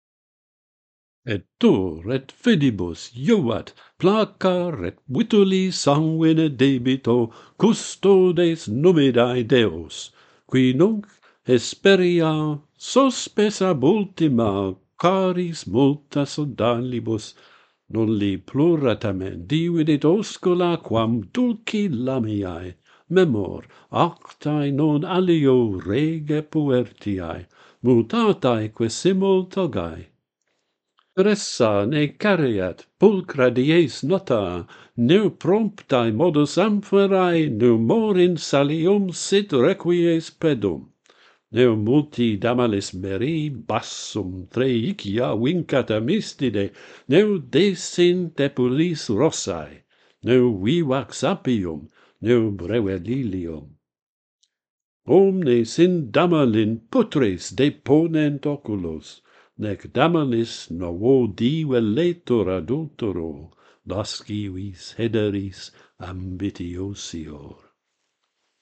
Numida's back - Pantheon Poets | Latin Poetry Recited and Translated
The metre alternates the standard twelve-syllable Asclepiadic line with its eight-syllable (“glyconic”) variant.